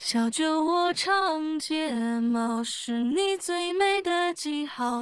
序号 说明 合成音频diffsinger_opencpop + pwgan_opencpop
4 从谱子获取 notesnote dursis_slurs不含 rest毛字一拍起始在小字一组第3组